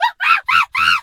monkey_2_scream_02.wav